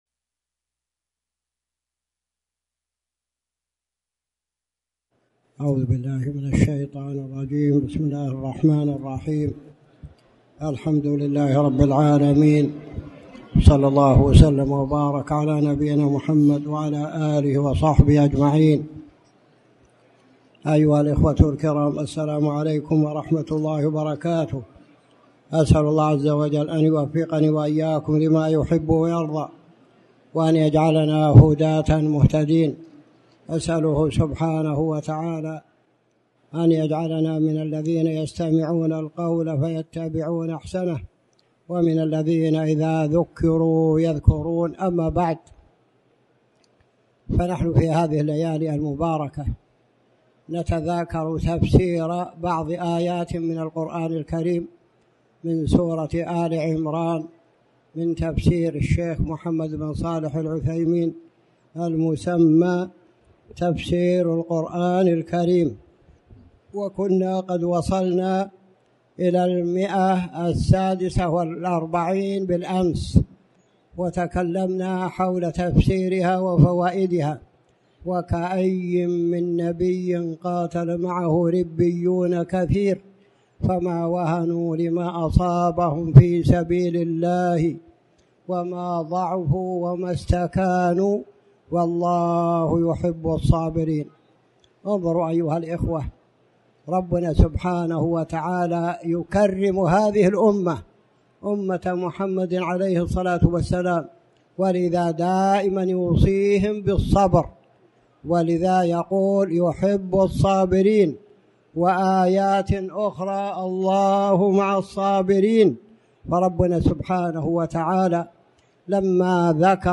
تاريخ النشر ٦ رمضان ١٤٣٩ هـ المكان: المسجد الحرام الشيخ